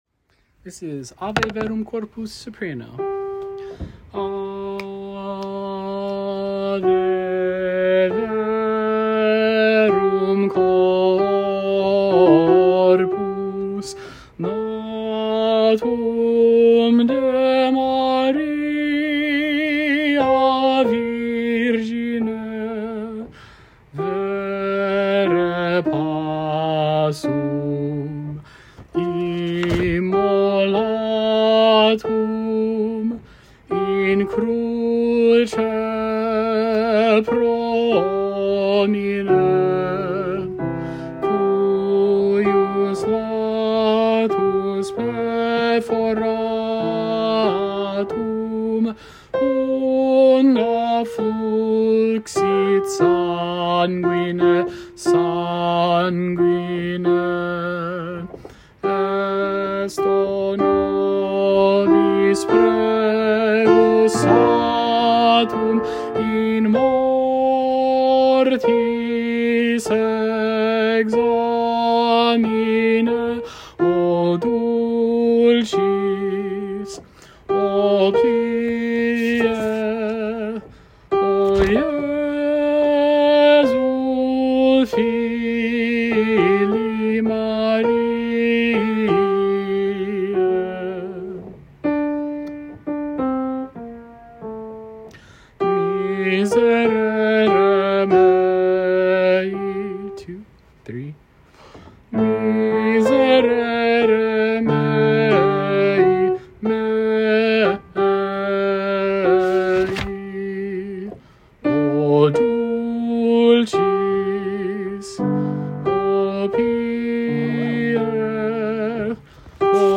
You can find PDF scores of each piece, along with a recording and when available, practice tracks for each voice part.
Soprano]
Ave-Verum-Corpus-Byrd-Soprano.m4a